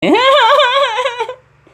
Laughing Dog